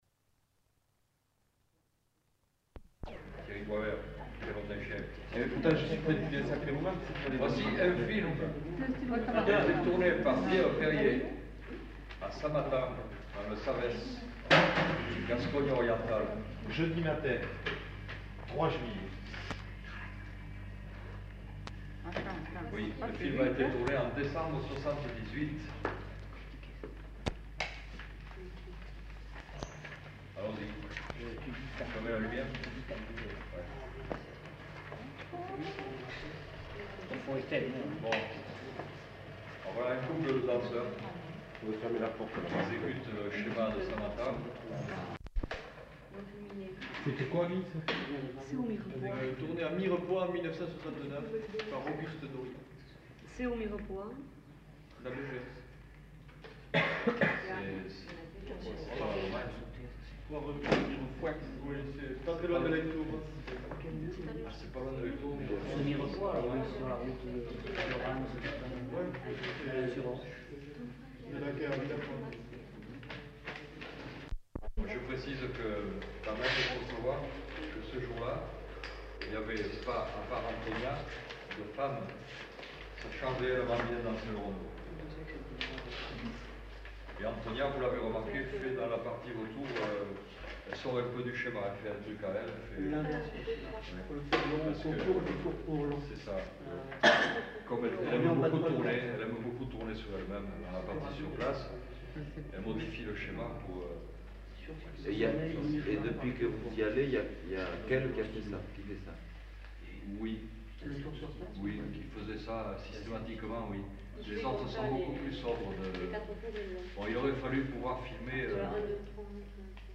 Nature du document : congrès
Type de son : mono
Qualité technique : moyen